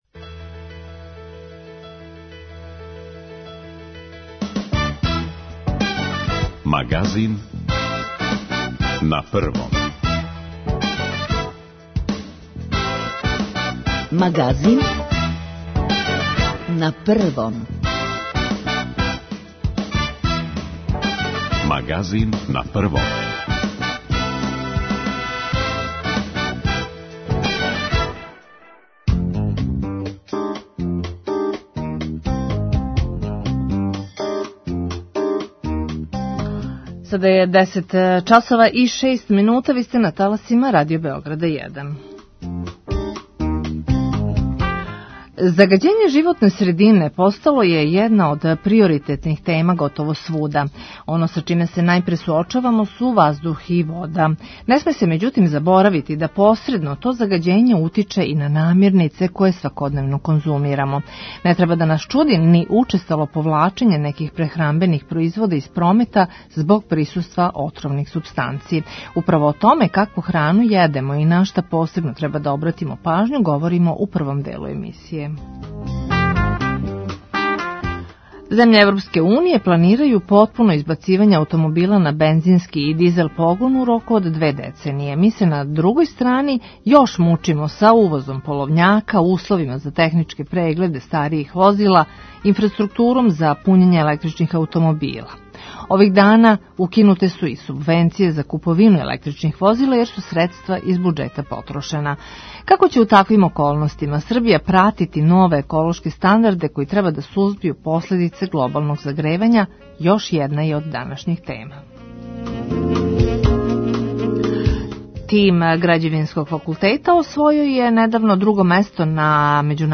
10:05 -> 14:19 Извор: Радио Београд 1 Аутор